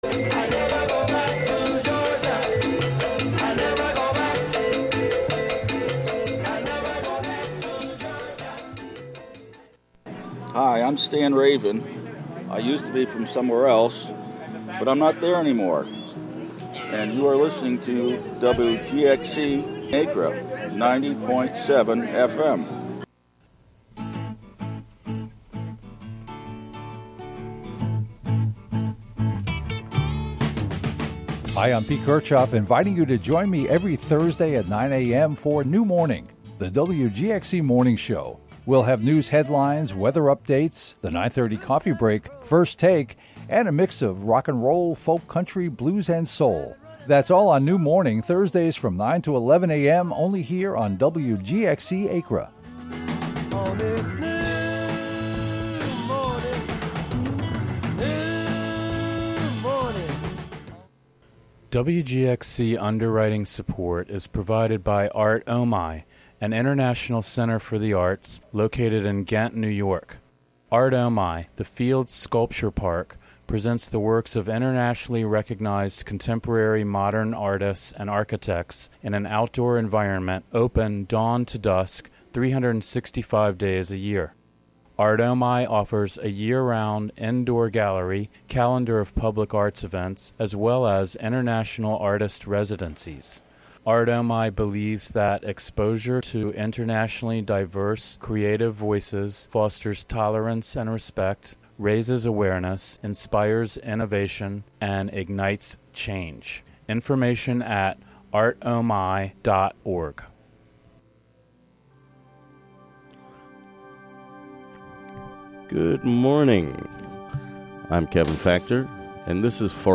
12pm Monthly program featuring music and interviews.